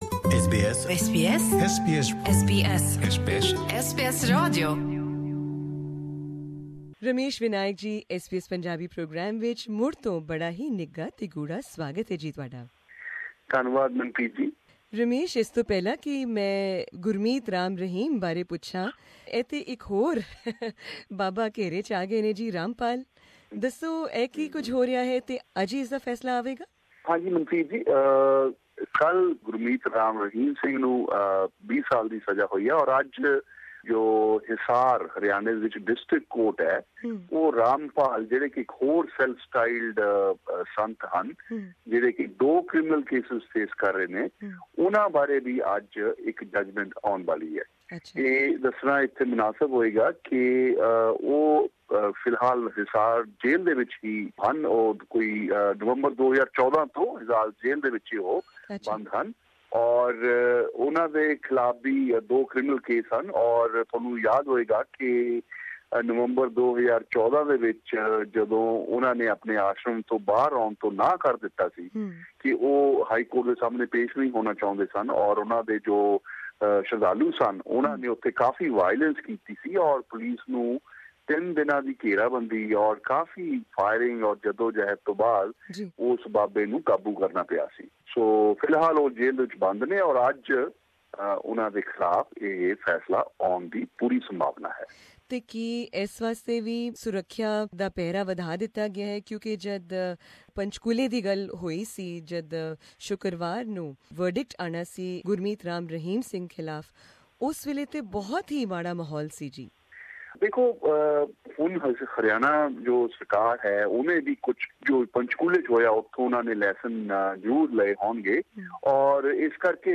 In his interview with SBS Punjabi